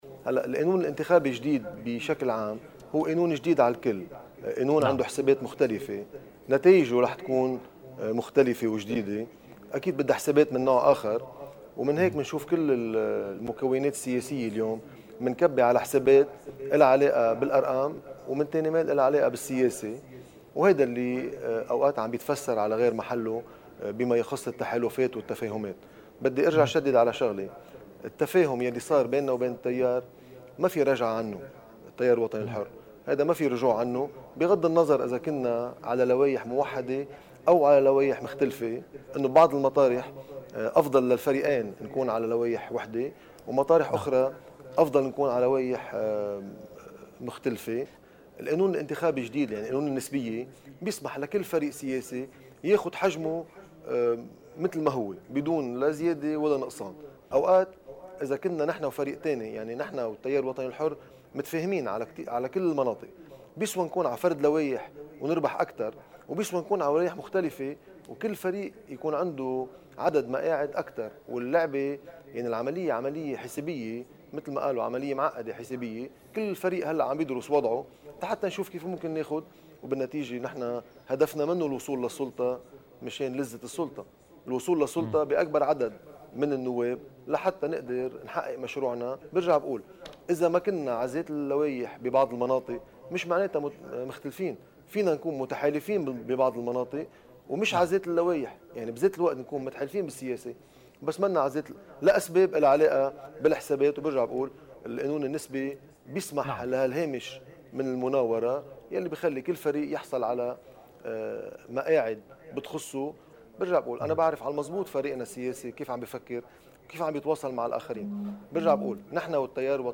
مقتطف من حديث مرشح القوات اللبنانية في البترون فادي سعد لقناة الـ”NBN”: